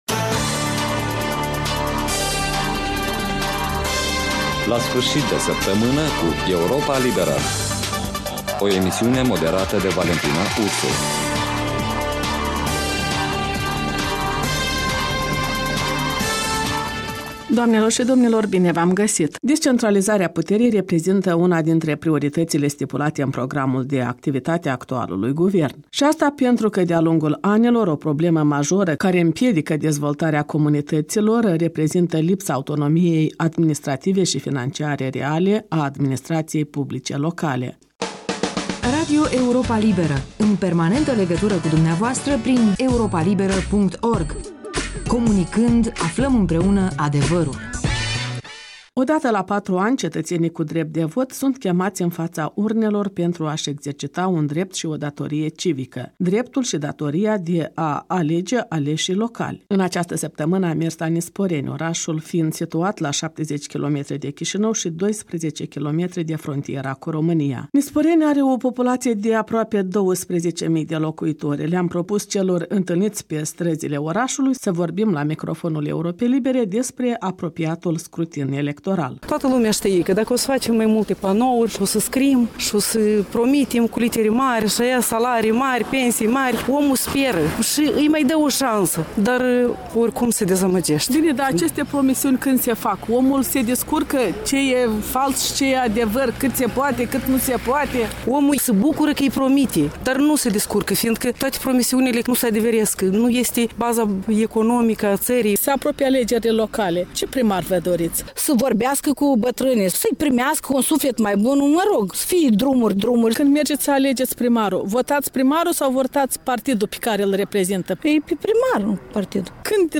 O pîine cîștigată greu: de vorbă cu primari locali în ajun de alegeri